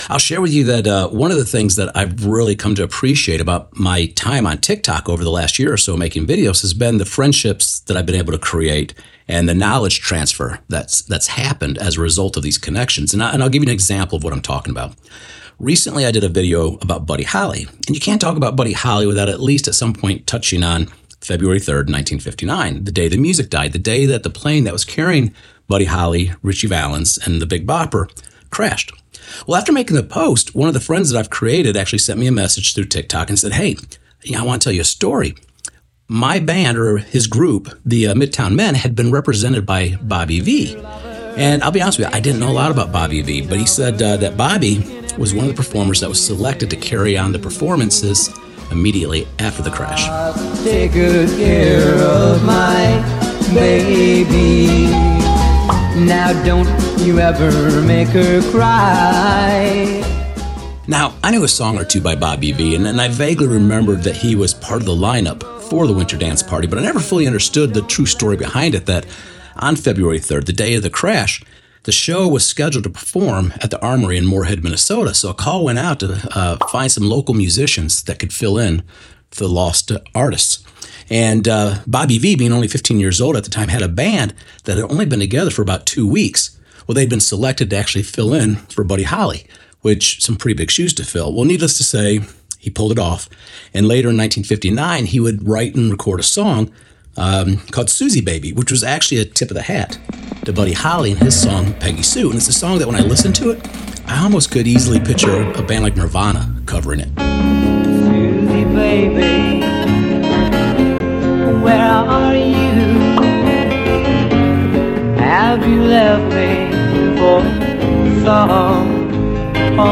Through interviews, rare recordings, and expert analysis, this episode offers a comprehensive look at the enduring legacy of Bobby Vee.